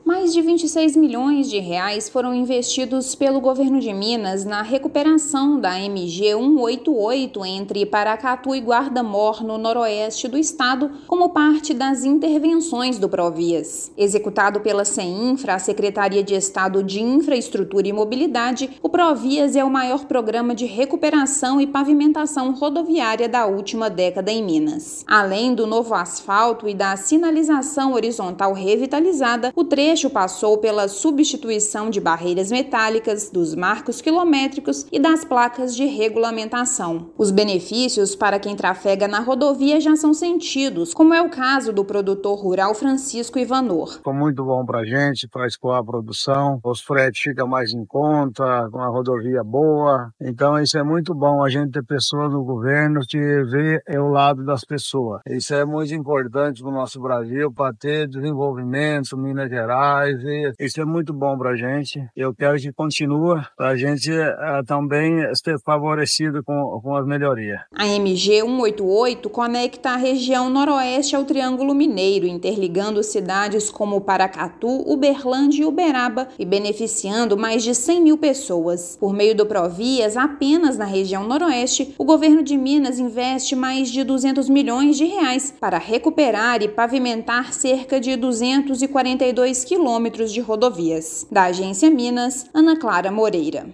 Mais de 72 quilômetros da MG-188, entre Paracatu e Guarda-Mor, ganharam novo asfalto. Ouça matéria de rádio.